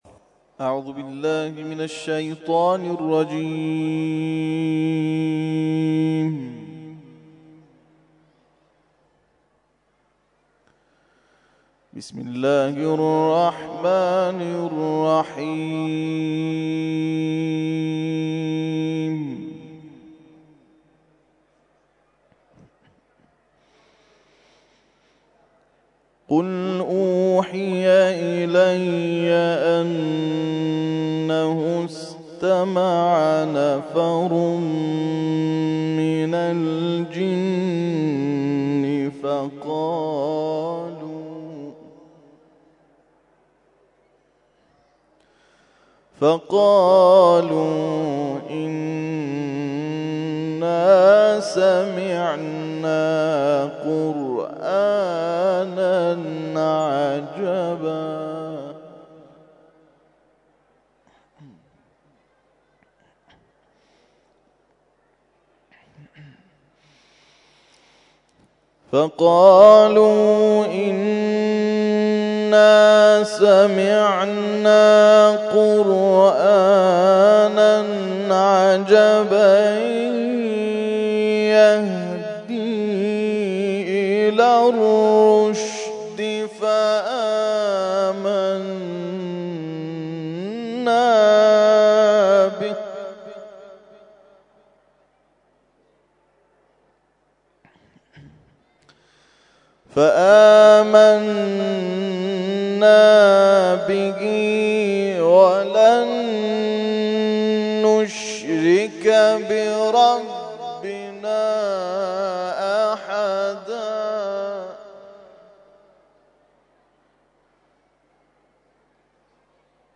تلاوت مغرب